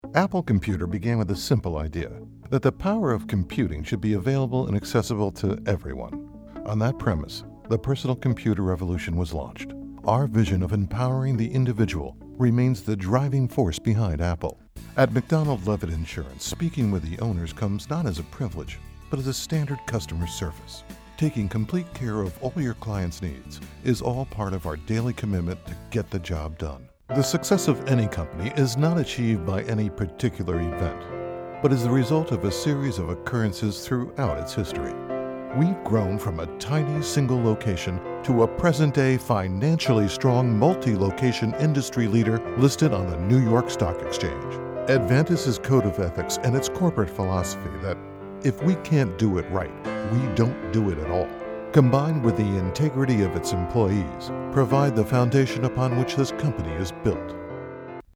Confident, sophisticated, strong, commanding, conversational, sexy, cool, wry, serious or tongue and cheek.
Sprechprobe: Industrie (Muttersprache):
My full service, State of the Art studio is based in midtown Manhattan, your final recording will be produced at broadcast quality.